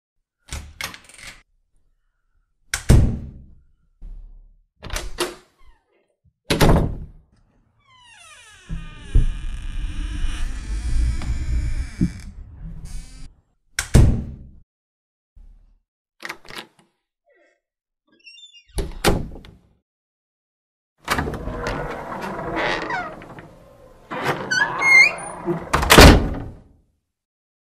دانلود صدای باز و بسته کردن در از ساعد نیوز با لینک مستقیم و کیفیت بالا
جلوه های صوتی
برچسب: دانلود آهنگ های افکت صوتی اشیاء دانلود آلبوم صدای باز و بسته شدن در از افکت صوتی اشیاء